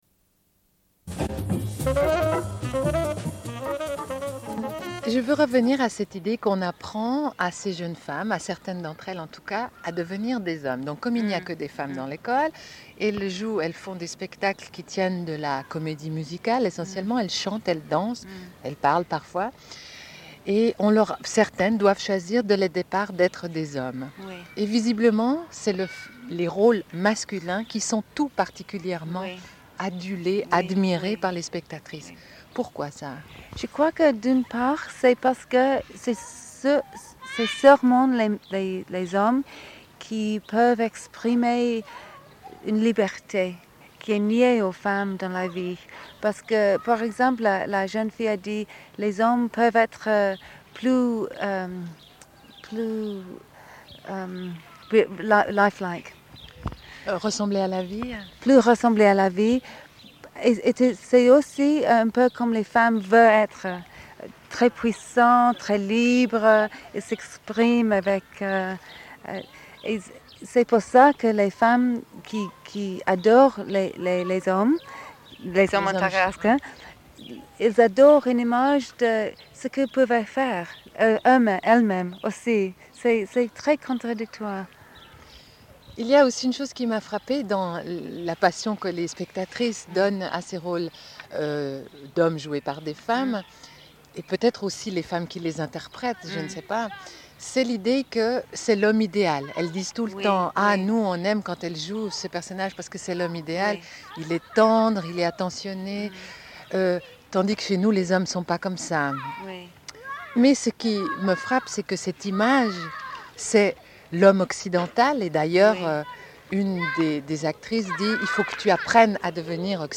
Une cassette audio, face B29:18
Sommaire de l'émission : autour du Festival International de films de femmes de Créteil. Diffusion d'enregistrements et d'entretiens réalisés sur place.